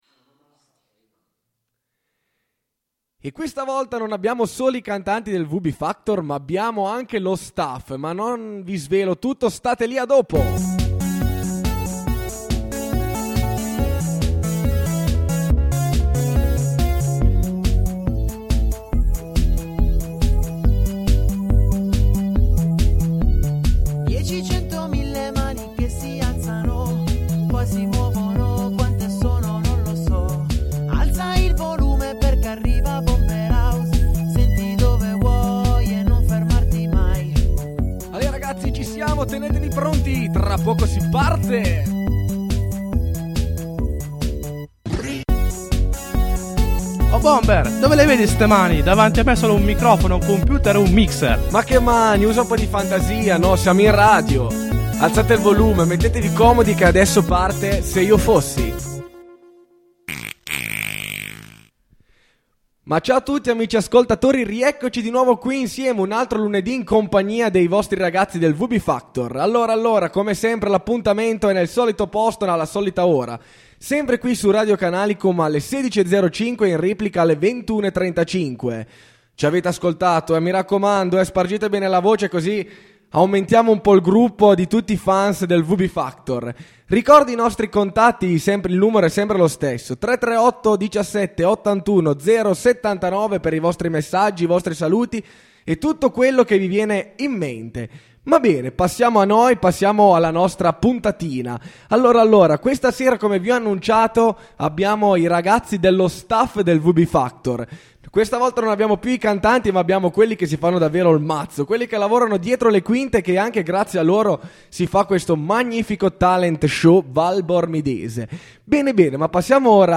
Partecipanti lo staff del vb factor